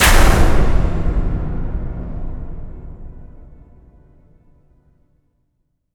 LC IMP SLAM 9B.WAV